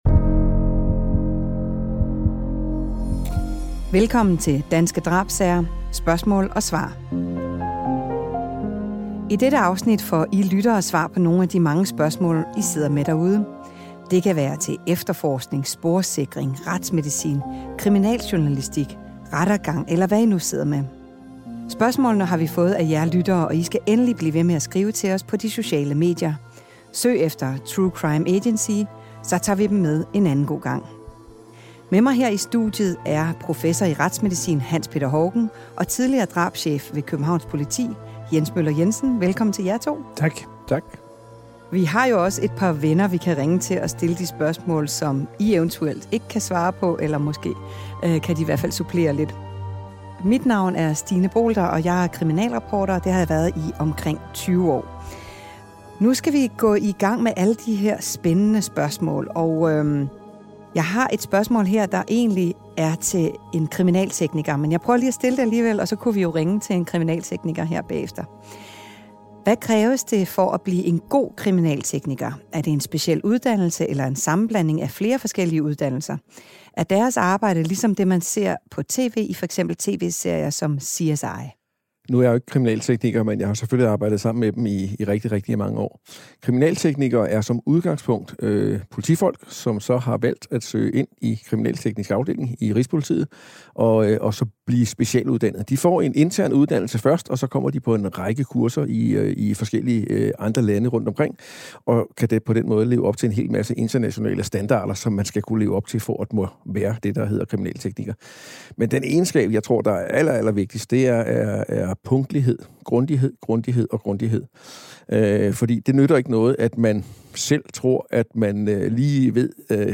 De medvirkende trækker tråde til sager, de selv har stået med og øser ud af deres store viden og indsigt.